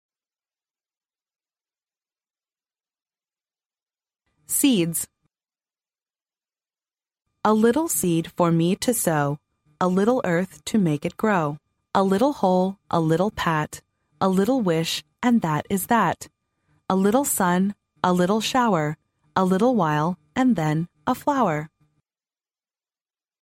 幼儿英语童谣朗读 第43期:种子 听力文件下载—在线英语听力室